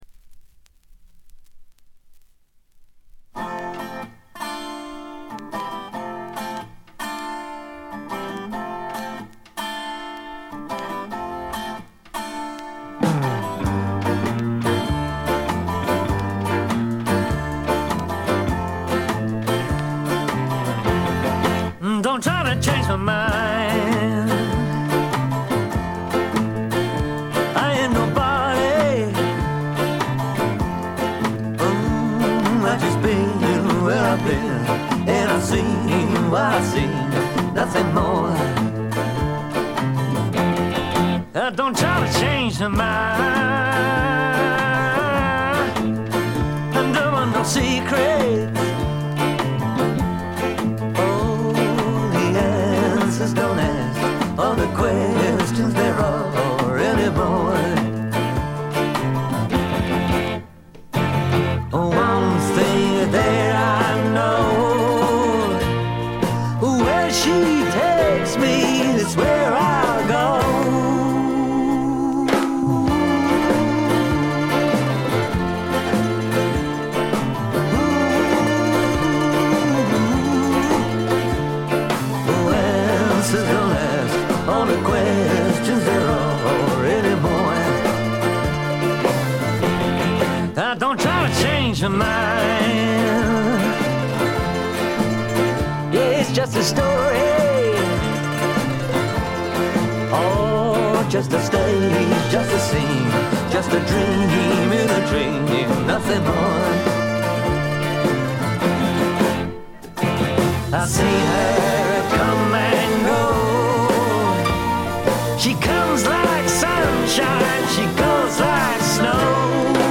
試聴曲は現品からの取り込み音源です。
guitar, mandolin, fiddle, keyboards, harmonica, vocals
violin, keyboards, harp, whistle
drums